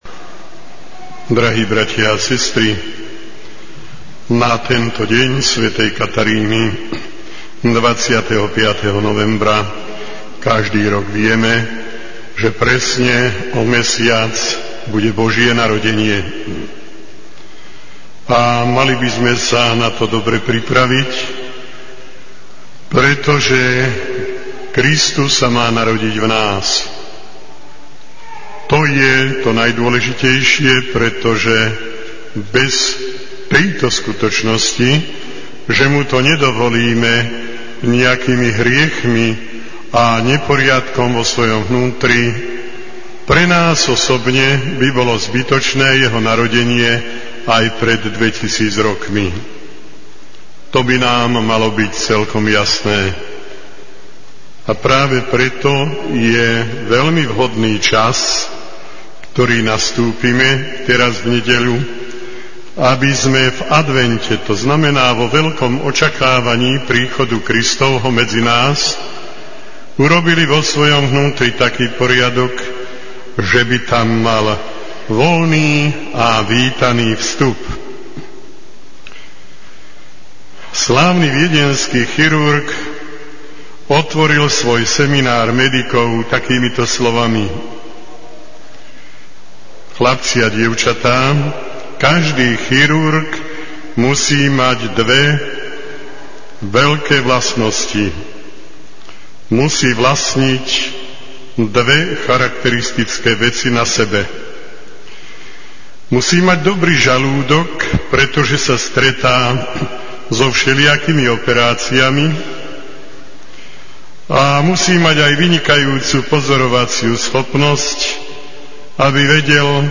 VÝBER Z HOMÍLIÍ OTCA BISKUPA RUDOLFA BALÁŽA
VÝBER Z HOMÍLIÍ OTCA BISKUPA RUDOLFA BALÁŽA (Ospravedlňujeme sa za zníženú kvalitu niektorých prvých nahrávok, ktorá bola spôsobená nami použitou technológiou záznamu.) v v v O múdrosti (Múd 13,1-9; Ž 19).